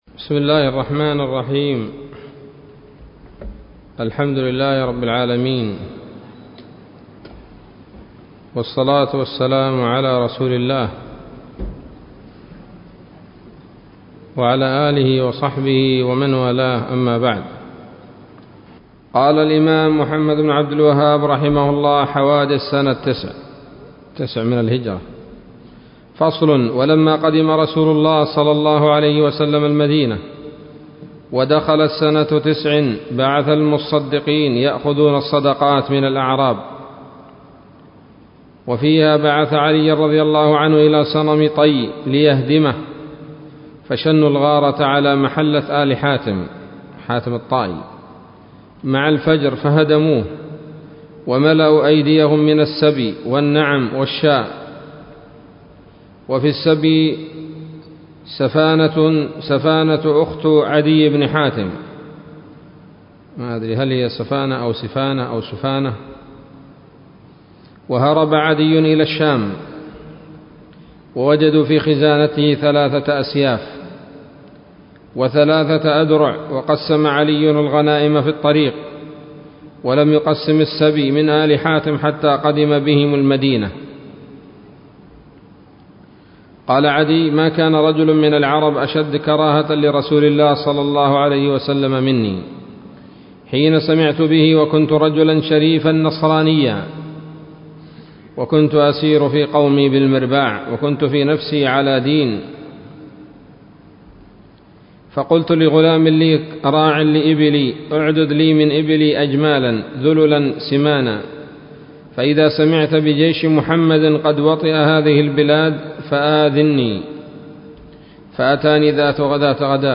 الدرس الحادي والخمسون من مختصر سيرة الرسول ﷺ